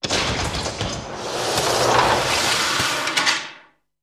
Guillotine
Guillotine Sequence And Elements; ( 1 ) Guillotine Decapitation Sequence; ( 2 ) Lever Releases Blade, Blade Slides Down; ( 3 ) Blade Impact